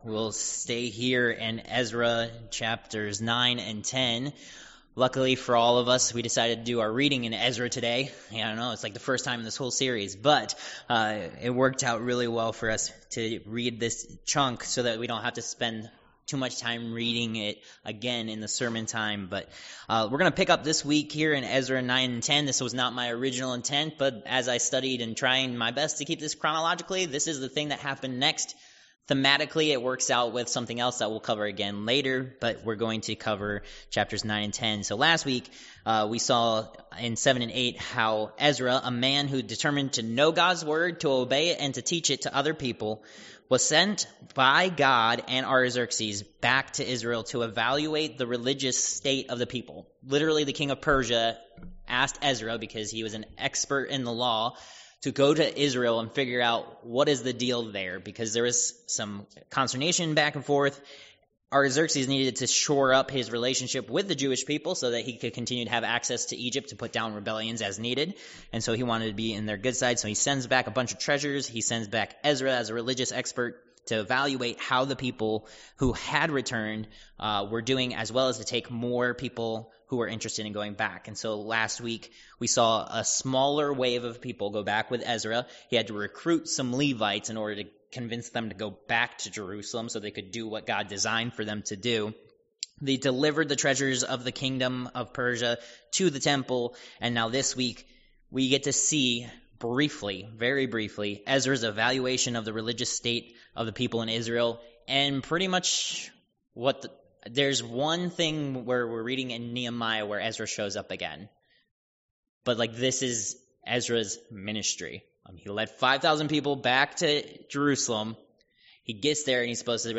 Ezra 9-10 Service Type: Worship Service « Protected